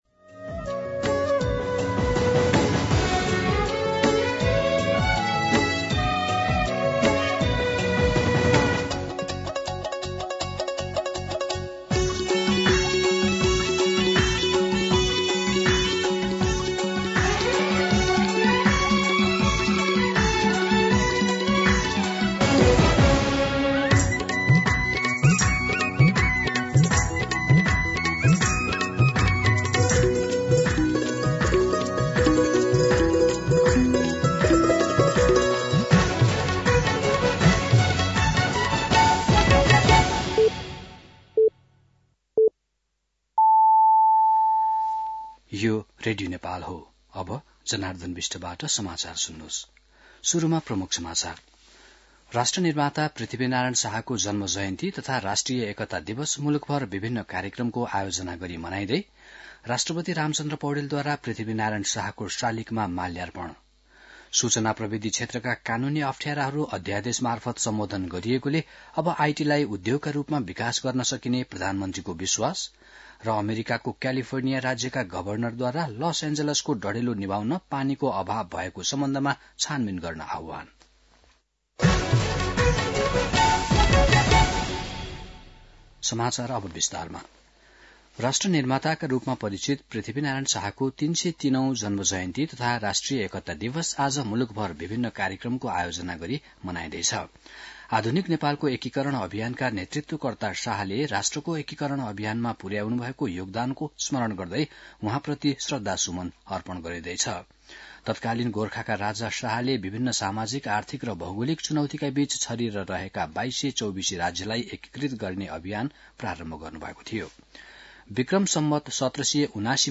दिउँसो ३ बजेको नेपाली समाचार : २८ पुष , २०८१
3-pm-Nepali-News-9-27.mp3